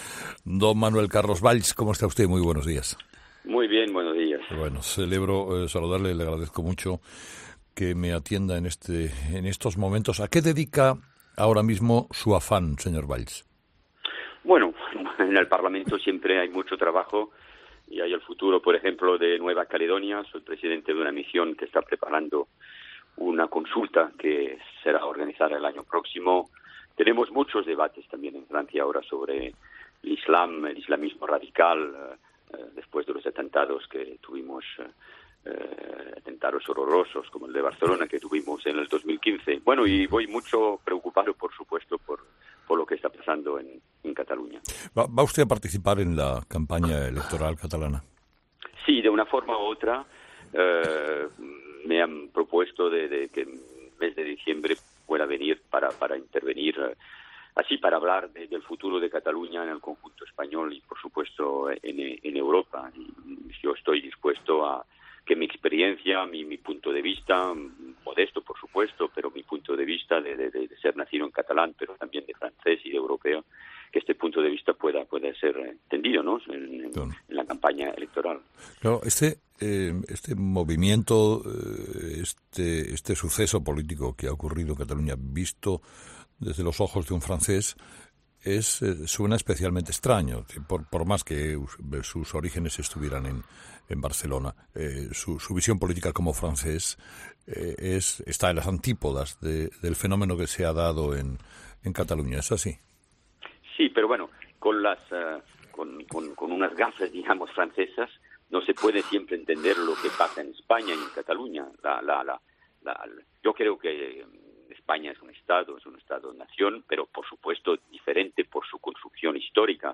Escucha la entrevista a Manuel Valls, ex primer ministro de Francia